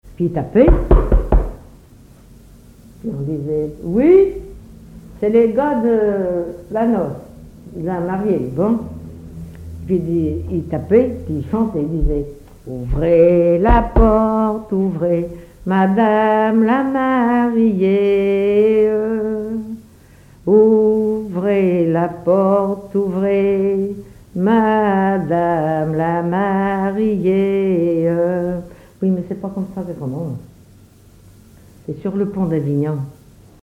collecte en Vendée
Témoignages et chansons traditionnelles